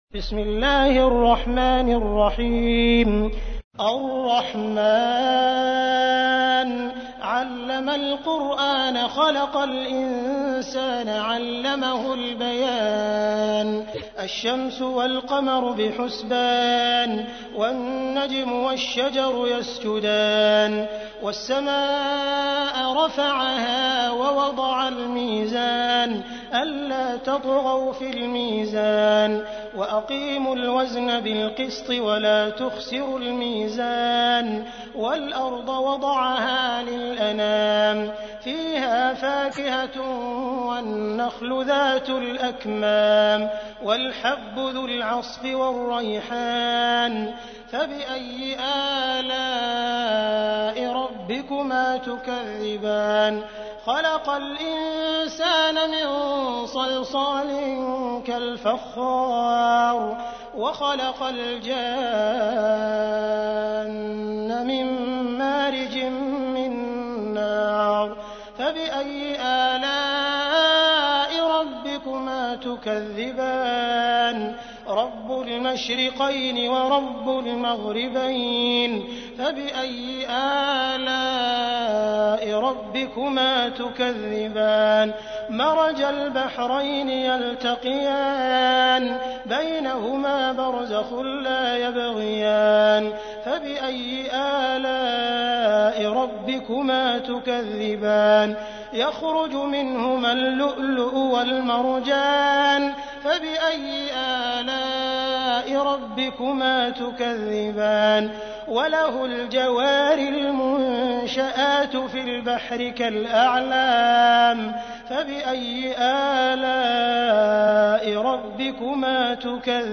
تحميل : 55. سورة الرحمن / القارئ عبد الرحمن السديس / القرآن الكريم / موقع يا حسين